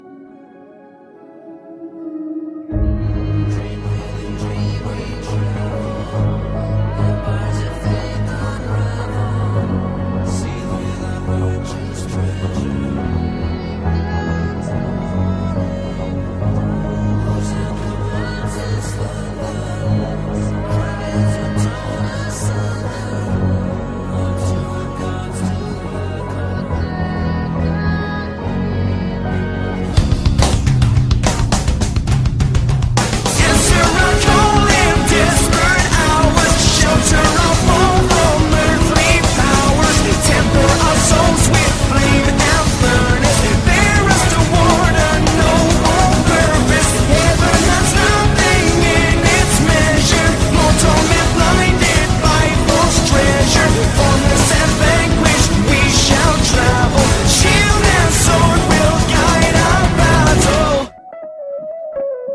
and 2. the words were too spread out for the fast-paced music.